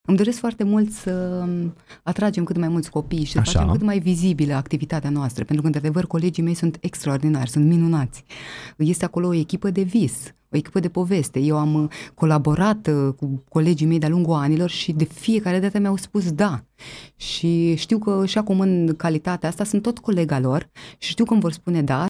Invitată în emisiunea „Față în față”, la Viva FM, aceasta a vorbit despre planurile pe care le are pentru perioada următoare și despre legătura specială pe care o are cu acest loc.